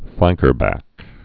(flăngkər-băk)